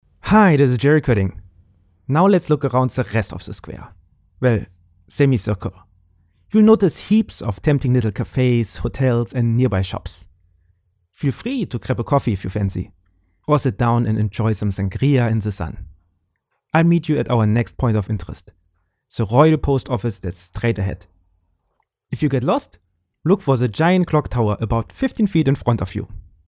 Male
Adult (30-50)
Tour Guide
Walking Tour With Heavy Accent
Male Voice Over Talent